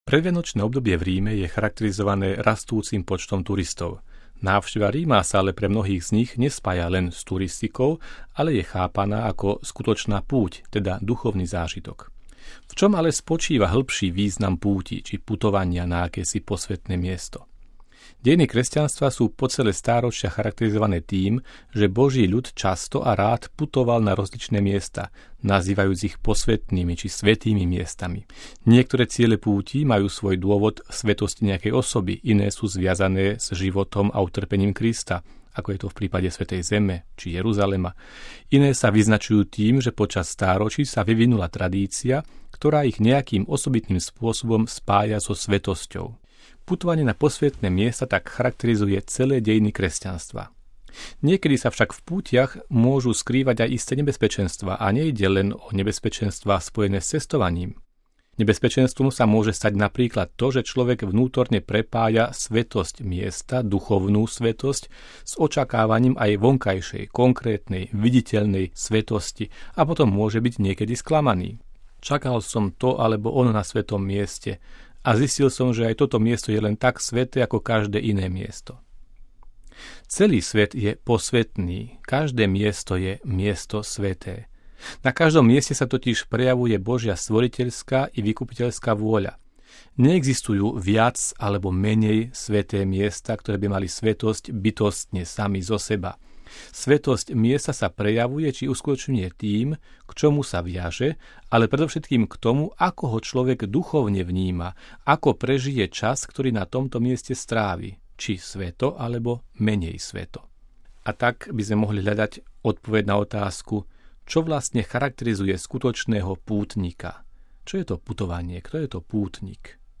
MP3 Úvaha Mons. Cyrila Vasiľa nad významom putovania v tradícii Cirkvi